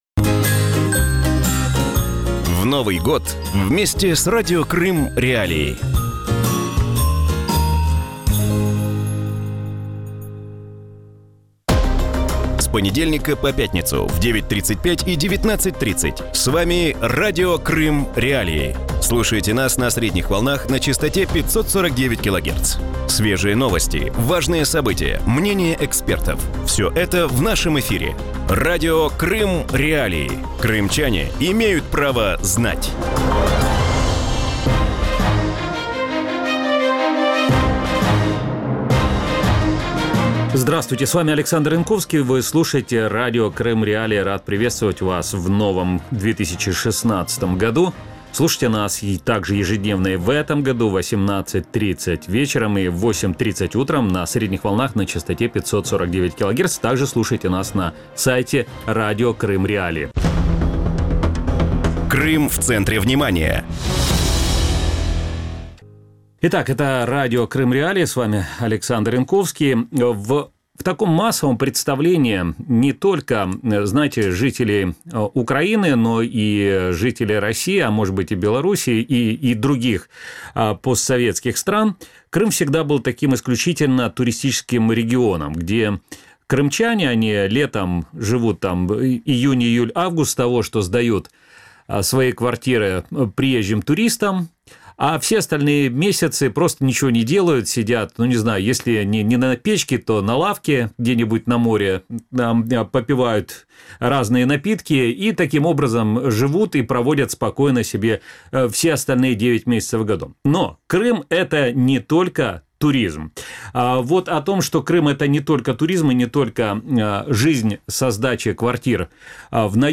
Сколько крымчан могут остаться без работы в сельском хозяйстве, возможно ли его возрождение в условиях оккупации, и стоит ли называть крымскими вина, произведенные на материковой Украине? Гость в студии Радио Крым.Реалии – советник министра аграрной политики и продовольствия Украины Александр Лиев